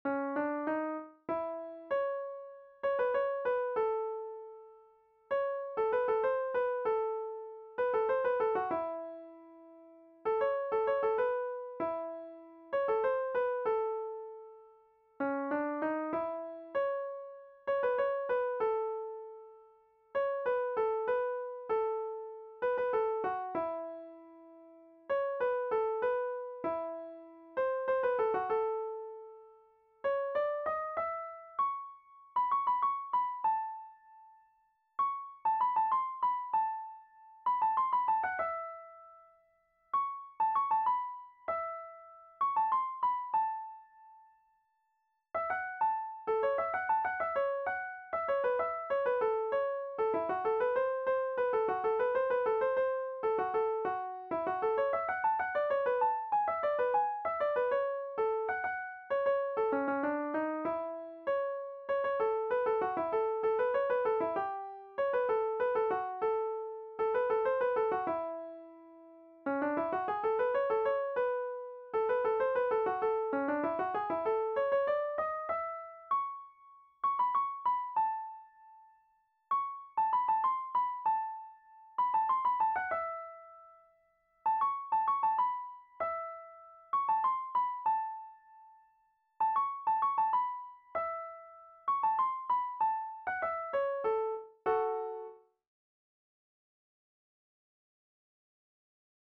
VIOLIN SOLO Violin Solo, Traditional, Fiddling Classic
DIGITAL SHEET MUSIC - VIOLIN SOLO